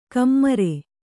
♪ kammare